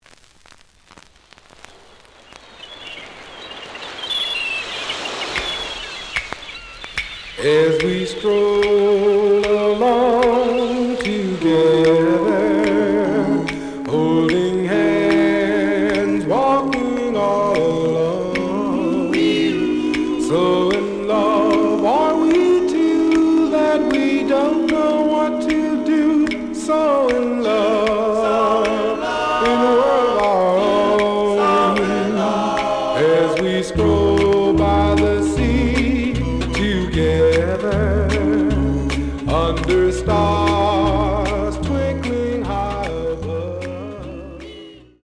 The audio sample is recorded from the actual item.
●Format: 7 inch
●Genre: Soul, 60's Soul